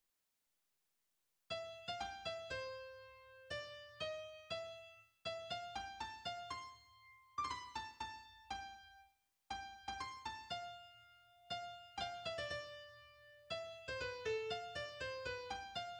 La pieza está escrita para la misma plantilla instrumental que el KV 313: cuerdas, dos oboes y dos trompas.
El único movimiento es un andante de 98 compases en 2/4 y en do mayor.